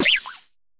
Cri de Tissenboule dans Pokémon HOME.